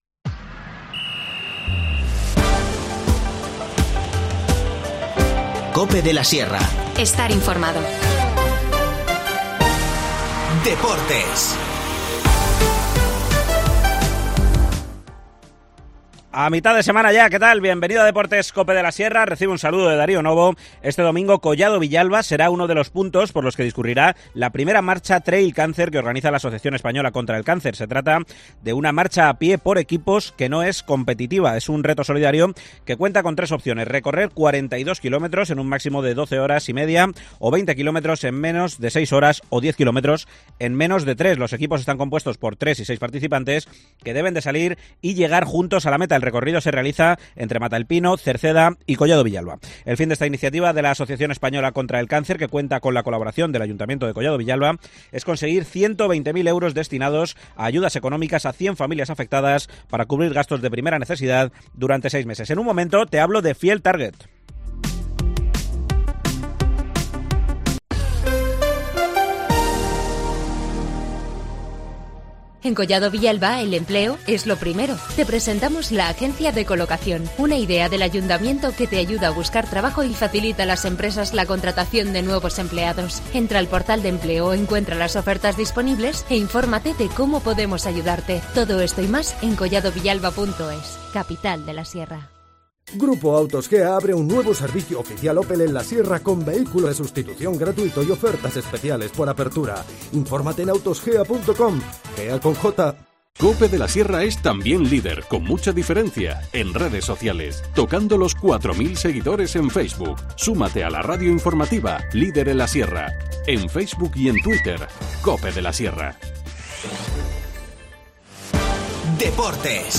El Field Target regresa a Valdemorillo este fin de semana. La Dehesa de los Godonales acogerá el segundo campeonato de España organizado por la Real Federación Española de Caza. Nos cuenta esta curiosa competición el concejal de Deportes, Miguel Partida.